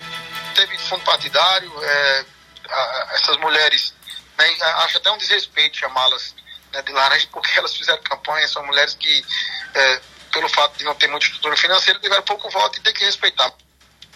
Em entrevista ao programa Arapuan Verdade, da Rádio Arapuan FM desta segunda-feira (16/12), o vereador do PDT, João Almeida, afirmou que as candidatas cumpriram toda legislação eleitoral e que obtiveram poucos votos devido ao baixo orçamento de campanha.